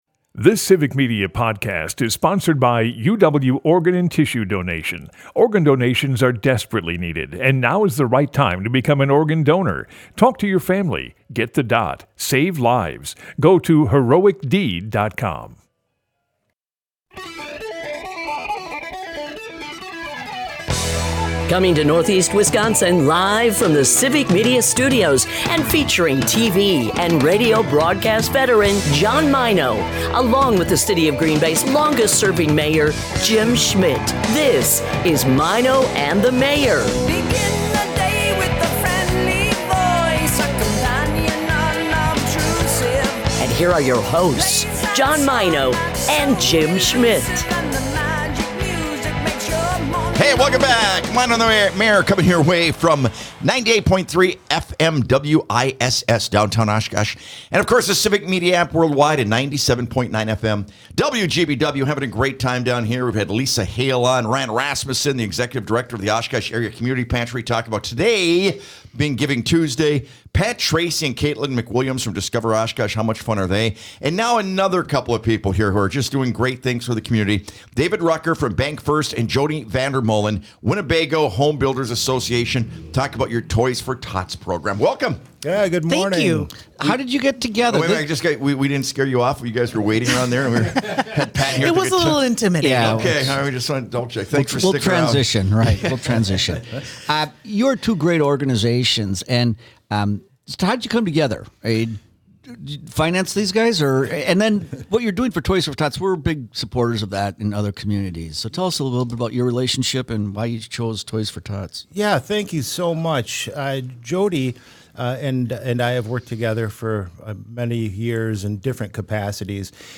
Maino and the Mayor is a part of the Civic Media radio network and airs Monday through Friday from 6-9 am on WGBW in Green Bay and on WISS in Appleton/Oshkosh.